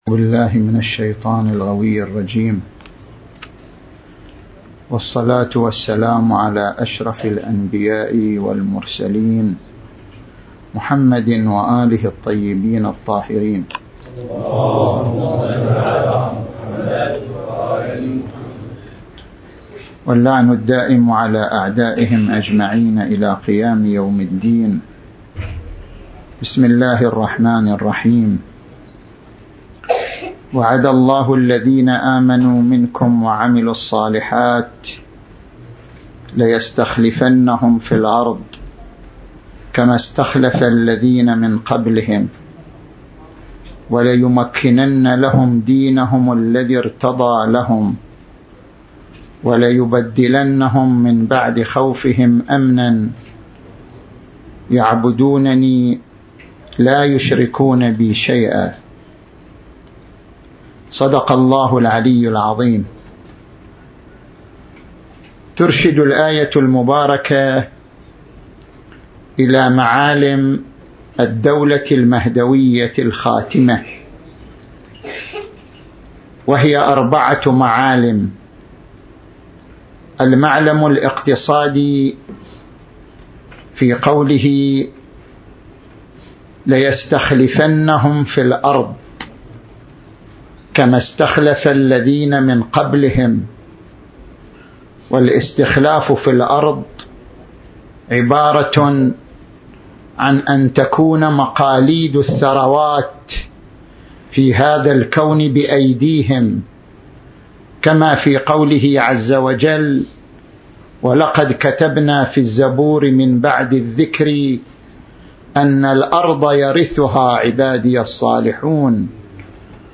المكان: العتبة الحسينية المقدسة/ قسم الشؤون الفكرية والثقافية/ شعبة الدراسات والبحوث الإسلامية